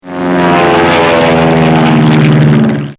دانلود آهنگ سوت هواپیما 9 از افکت صوتی حمل و نقل
دانلود صدای سوت هواپیما 9 از ساعد نیوز با لینک مستقیم و کیفیت بالا
جلوه های صوتی